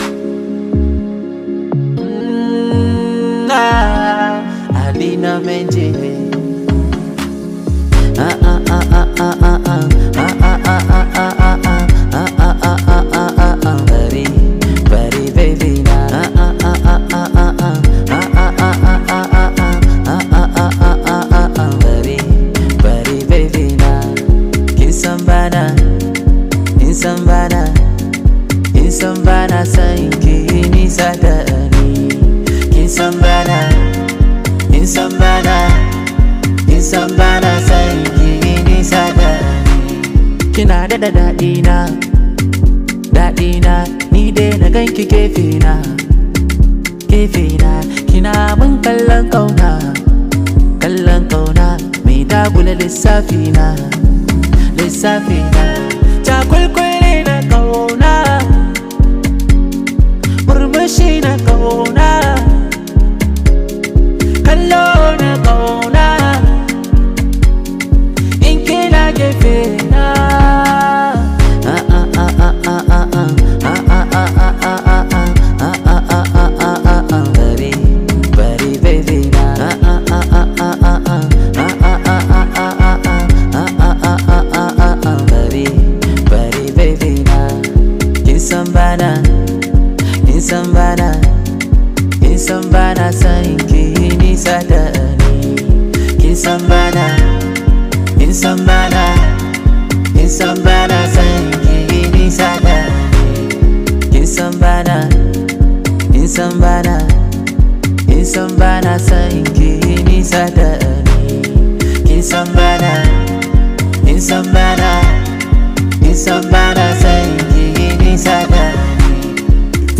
hausa song
high vibe hausa song